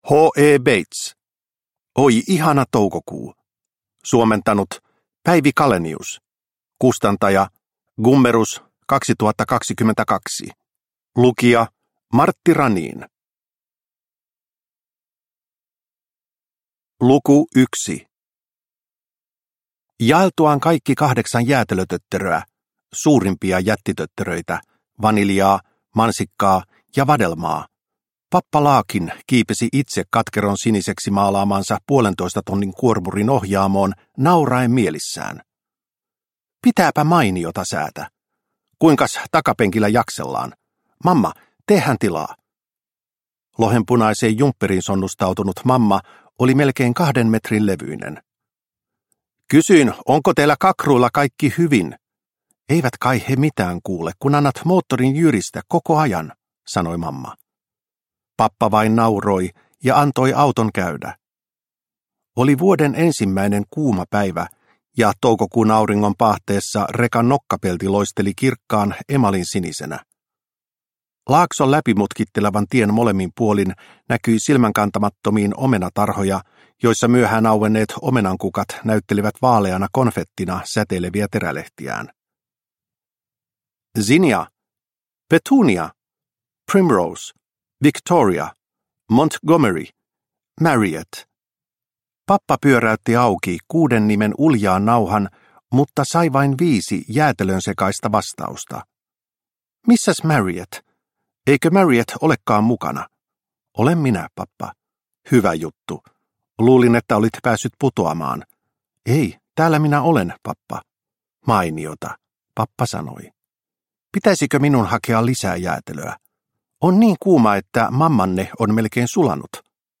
Oi ihana toukokuu – Ljudbok – Laddas ner